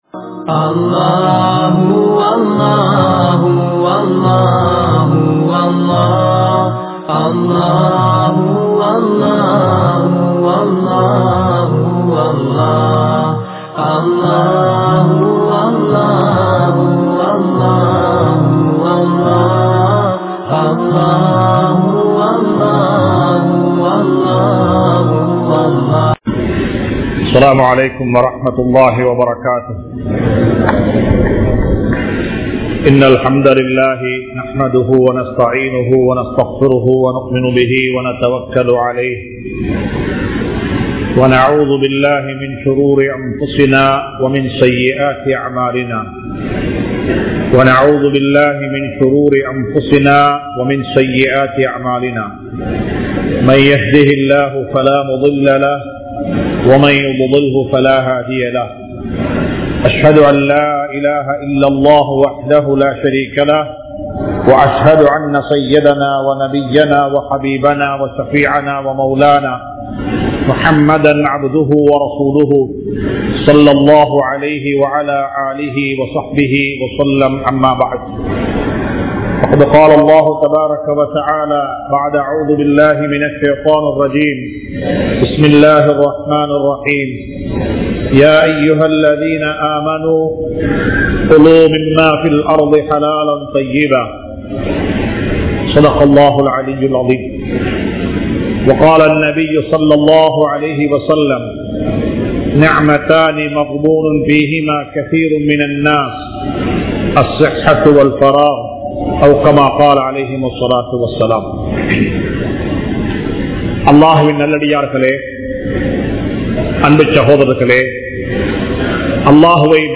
Allah Thanthulla Niumath Aaroakkiyam (அல்லாஹ் தந்துள்ள நிஃமத் ஆரோக்கியம்) | Audio Bayans | All Ceylon Muslim Youth Community | Addalaichenai
Dehiwela, Muhideen (Markaz) Jumua Masjith